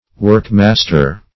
Search Result for " workmaster" : The Collaborative International Dictionary of English v.0.48: Workmaster \Work"mas`ter\, n. The performer of any work; a master workman.